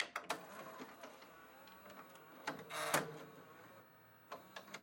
На этой странице вы найдете подборку звуков видеомагнитофона: характерное жужжание двигателя, щелчки кнопок, фоновые шумы аналоговой записи.
Звук запуска видеомагнитофона VCR